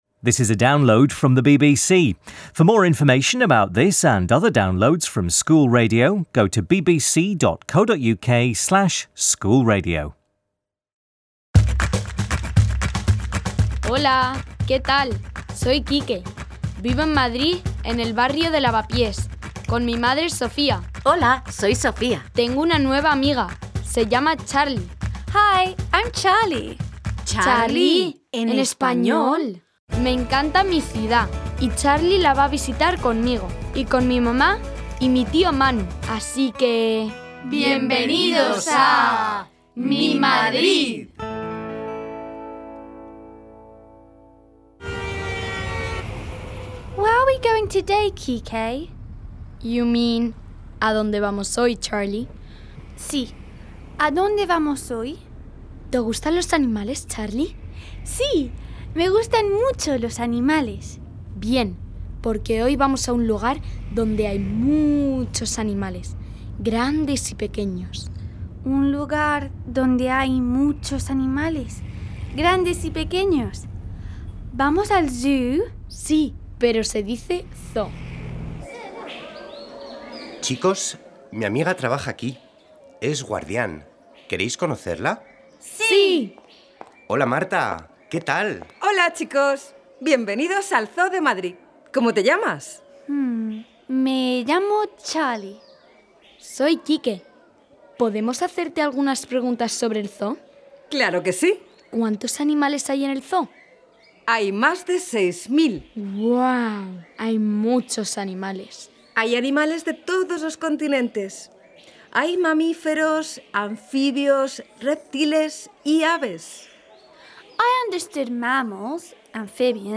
Uncle Manu takes Charlie and Quique to the zoo where they meet Marta, one of the zookeepers. Teresa tells a story about Ramón el Ratón looking for his friend with the help of Gael el Gorila and Uncle Manu sings about some of the zoo animals. Vocabulary includes animals and colours and grammar includes the gender of nouns and adjectival agreement with singular nouns.